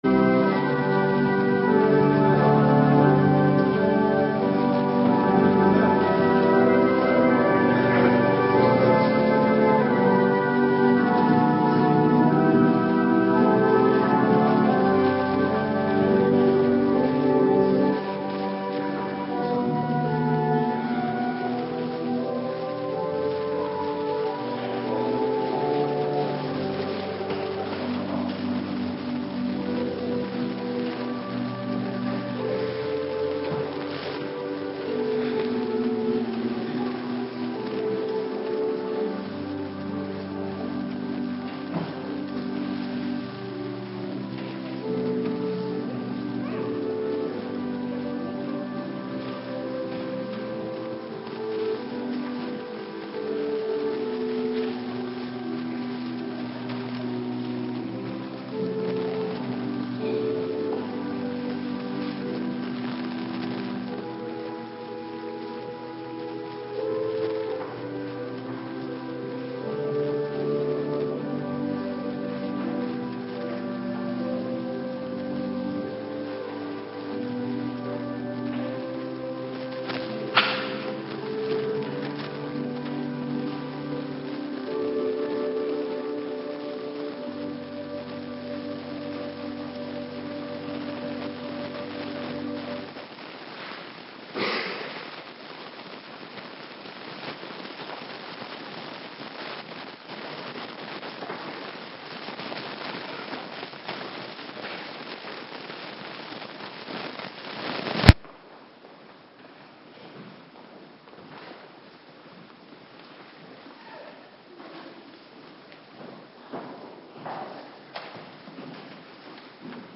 Morgendienst bediening Heilige Doop - Cluster 1
Locatie: Hervormde Gemeente Waarder